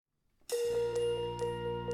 Gattung: Konzertstück für Blasorchester
Besetzung: Blasorchester